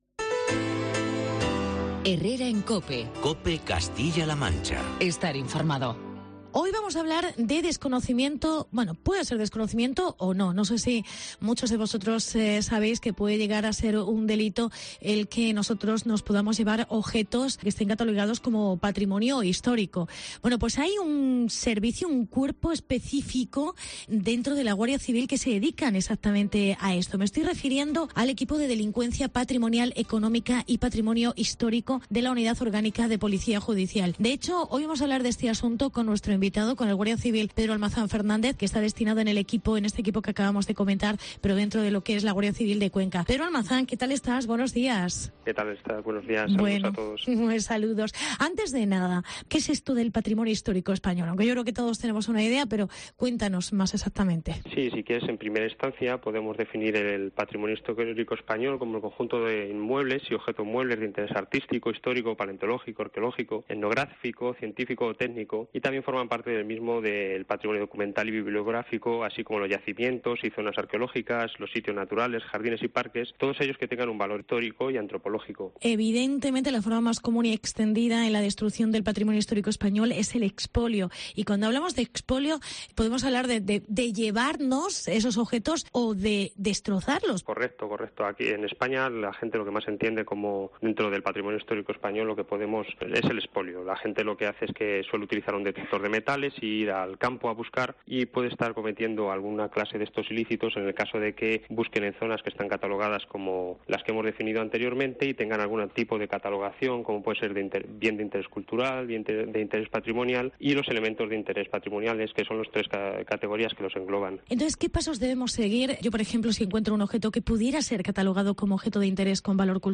Delitos contra el Patrimonio cultural e histórico. Entrevista